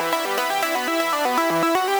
SaS_Arp05_120-E.wav